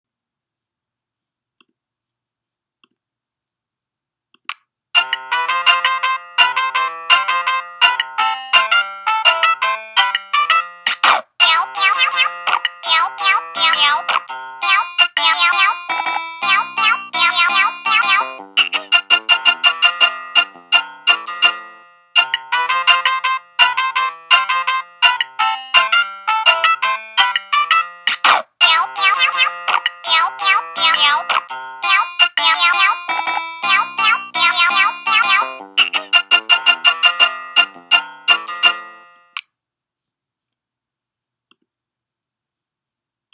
no it is totally my fault. i managed to figure it out but the audio quality is horrible, which is the best this song deserves. i believe that the purpose of mario paint is to create things that you repeat ad nauseum until you lose your mind, and i think i succeeded